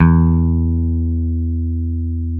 Index of /90_sSampleCDs/Roland L-CD701/GTR_Dan Electro/BS _Dan-O Bass